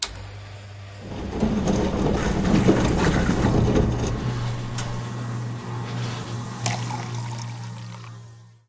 dispense2.ogg